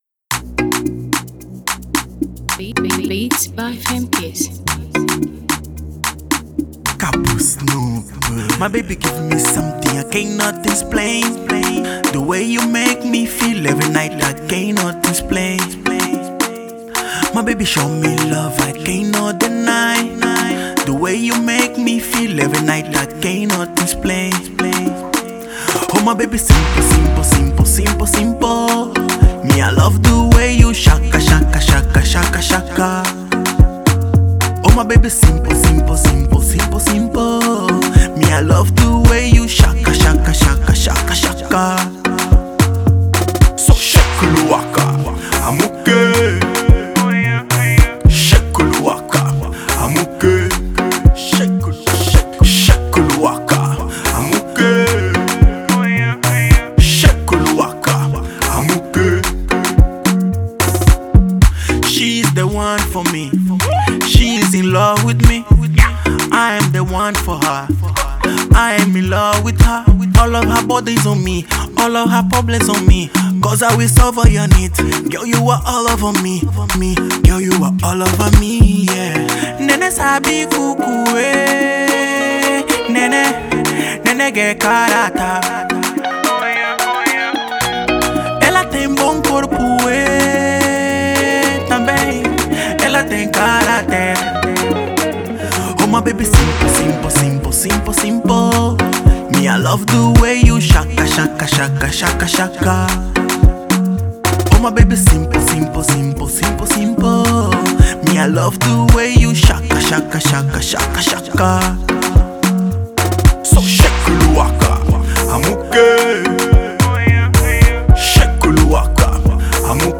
Angolan singer